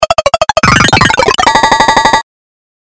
描述：A perfect (sample accurate) 1second loop of a synthetic power line hum of 50 Hz. Created using ZynAddSubFX, LMMS and Audacity.
标签： loop line sfx buzz zap game hum electricity power electric noise
声道立体声